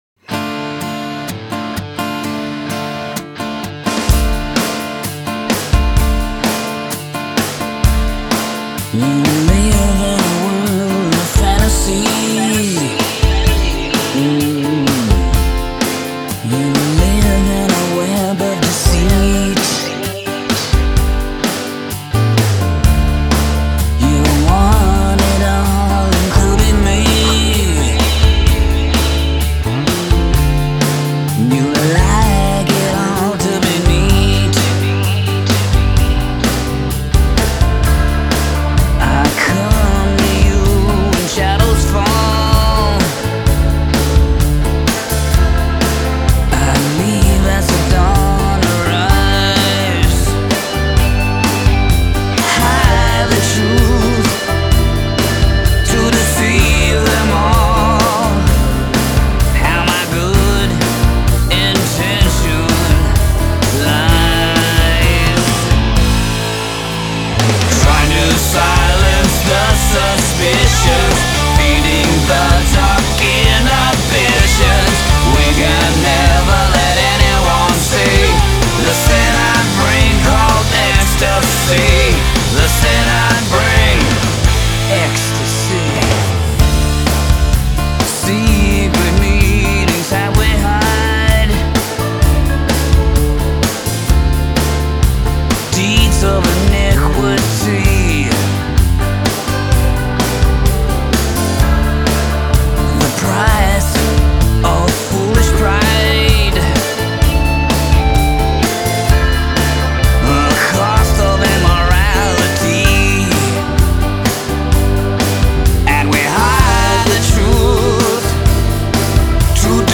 Hard Rock heavy metal